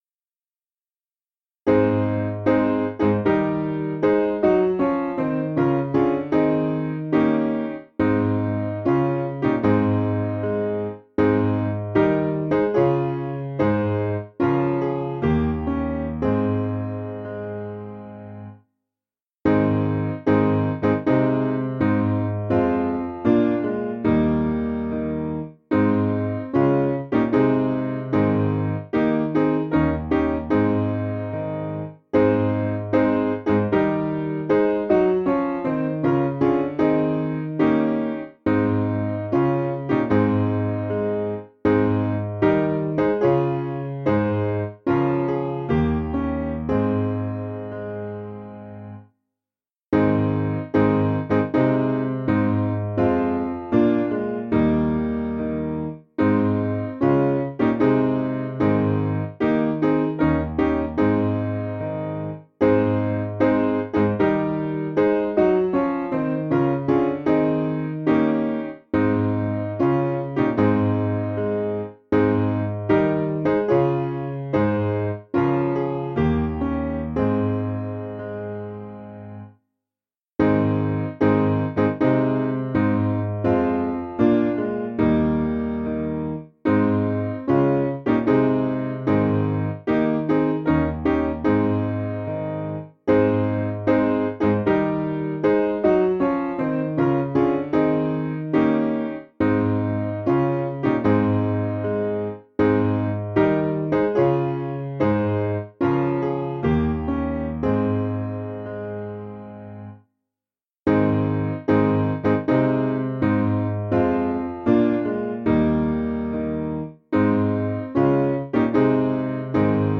Simple Piano
(CM)   4/Ab 475.5kb